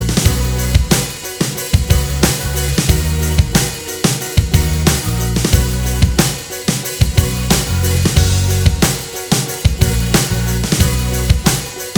Minus All Guitars Pop (2010s) 3:05 Buy £1.50